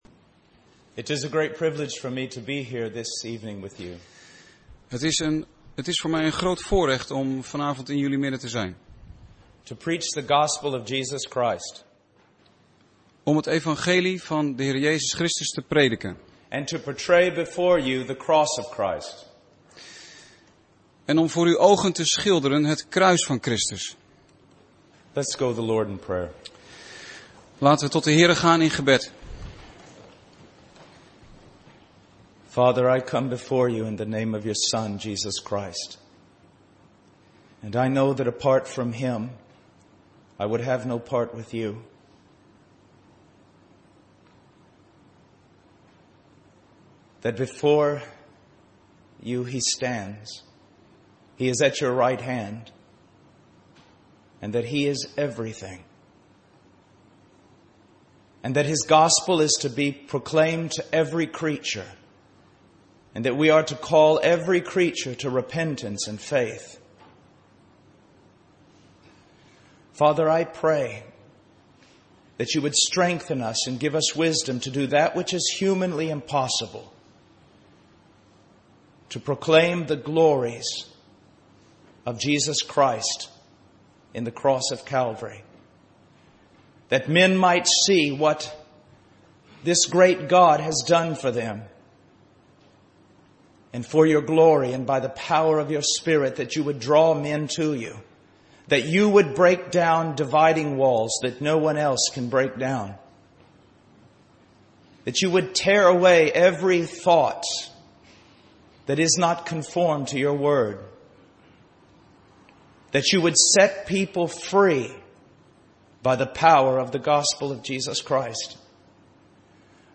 Een preek over 'Het evangelie van Jezus Christus'.